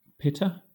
Ääntäminen
Southern England: IPA : /ˈpɪtə/
GA : IPA : /ˈpiːtə/